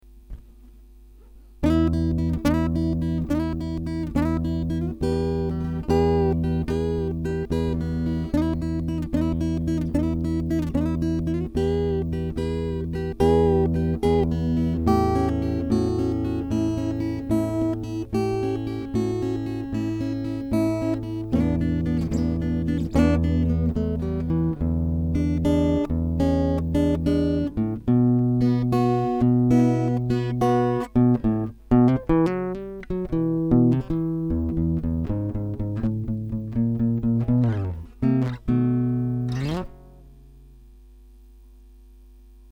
Alors voila deux trois samples (massacres) de ma petite gratte une Ibanez PF60Ce, rien à voir avec les canons du topic .
C'est enregistré avec la gratte branchée directementen jack et Audacity.